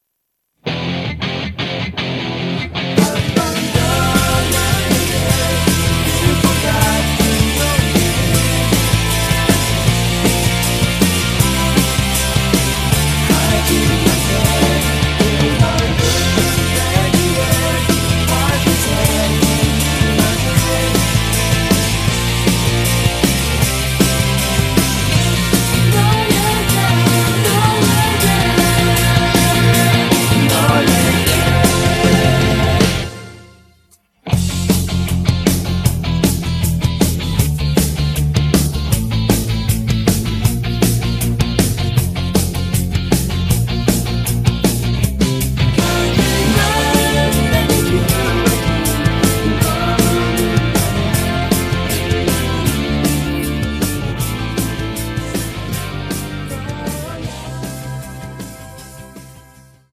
음정 -1키 3:13
장르 가요 구분 Voice MR